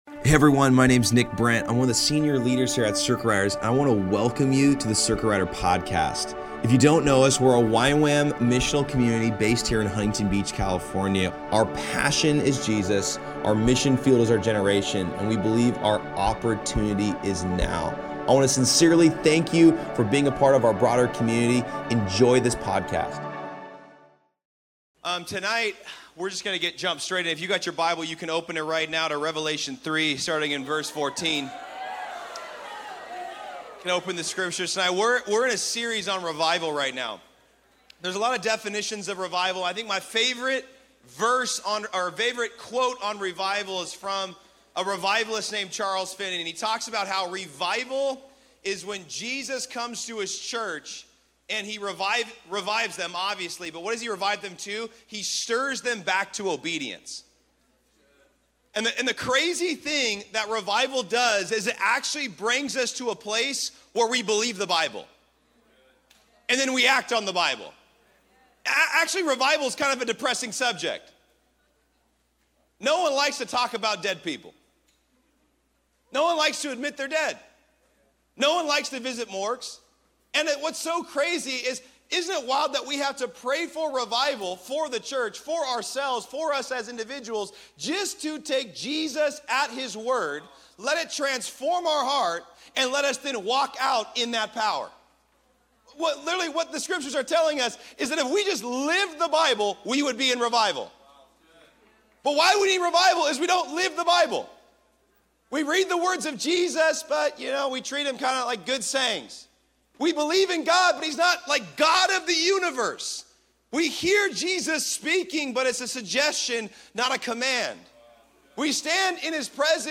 Costa Mesa
Message